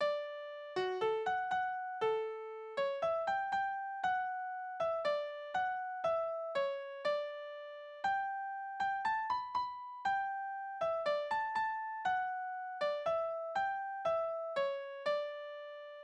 Balladen: Das Mädchen mit den 2 Liebhabern
Tonart: D-Dur
Taktart: 4/4
Tonumfang: Undezime
Besetzung: vokal